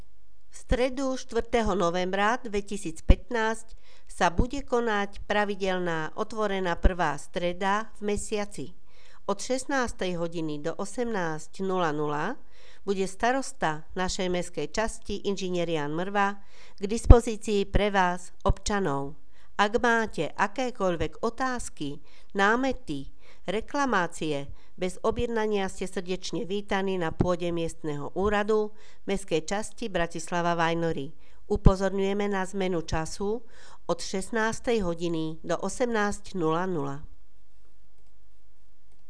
Hlásenie miestneho rozhlasu 2.11.2015 (stretnutie so starostom 4.11.)